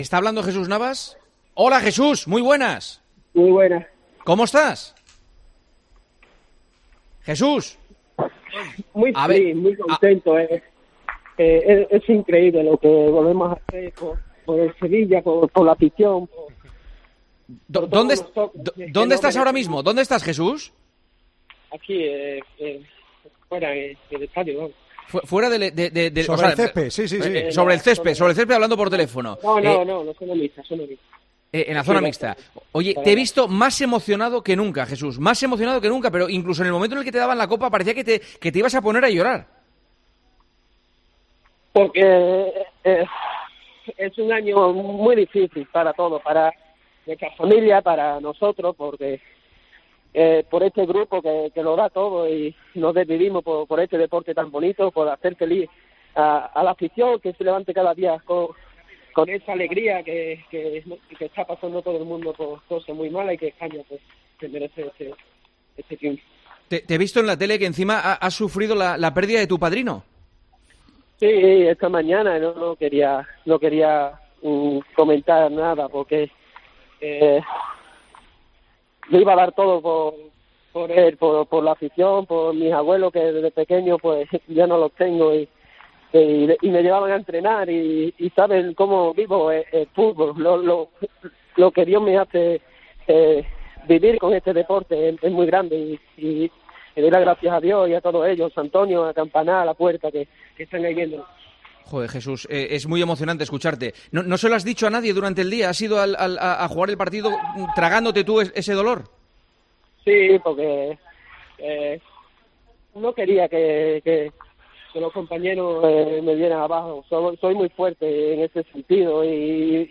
El capitán del Sevilla, Jesús Navas, analizó en los micrófonos de El Partidazo de COPE el sexto título de Europa League conseguido ante el Inter.